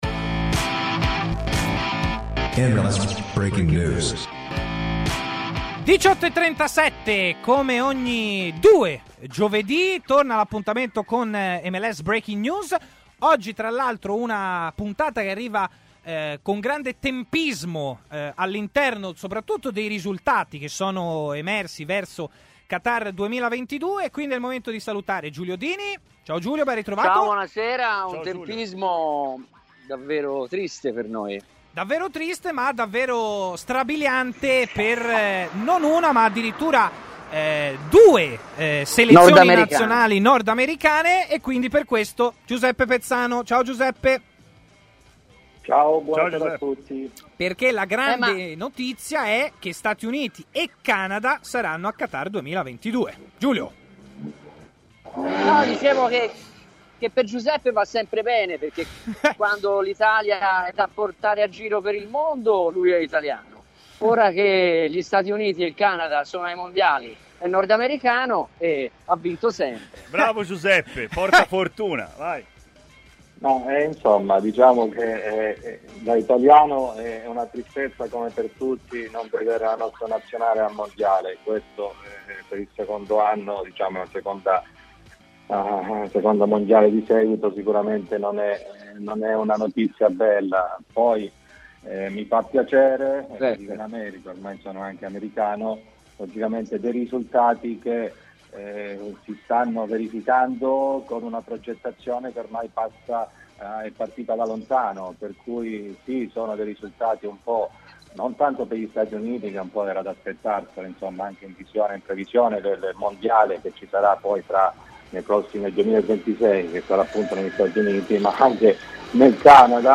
ha parlato in diretta su TMW Radio durante MLS Breaking News, trasmissione.
L'intervento integrale nel podcast